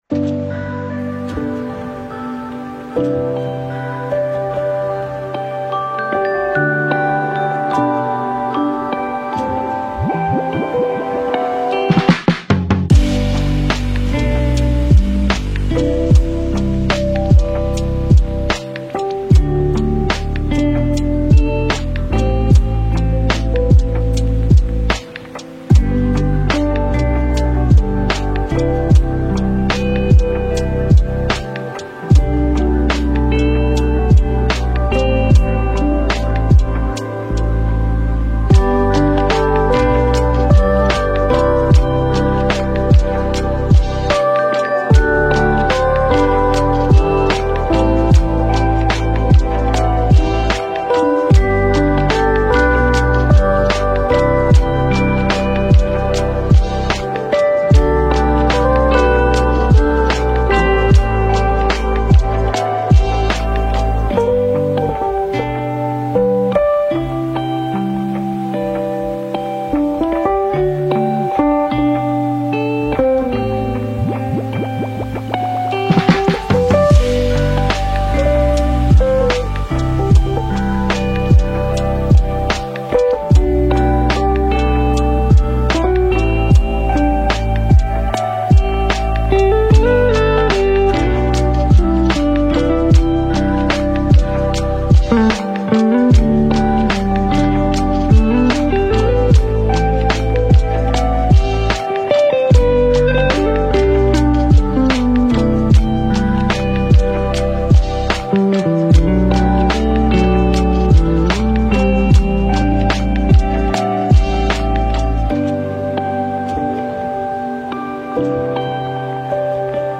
Мы подобрали для вас лучшие lo-fi песни без слов.
Зимняя мелодия ❄